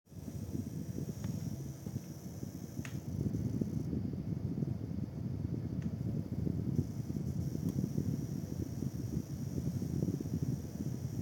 Rauschendes Geräusch beim Starten jeglicher Spiele
Hallo mein pc macht seit neustem ein rauschendes geräusch beim spielen specs: Cpu: Intel core i5 10400F GPU: Powercolor Red Dragon RX6800 PSU...
Hier ist das Rauschen wenn ich die Fps runter stelle wird es leiser